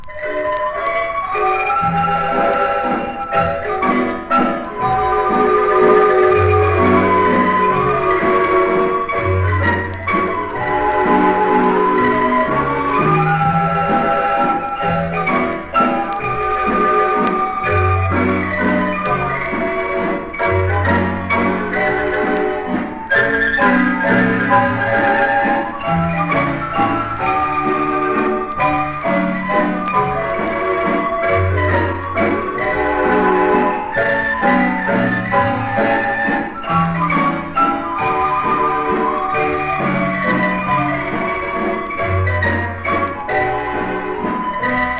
こちらは現地録音盤で、メキシコの観光都市オアハカのマリンババンドが演奏する様を収録。地元の演奏家らによる9人編成と3人編成バンドの音が録音としてはざらっとしていていながら、街の空気に響き溶けるような様が素晴らしい。打鍵の熱量、生み出されるポコポコとした音がとても心地良いです。
World, Field Recording　USA　12inchレコード　33rpm　Mono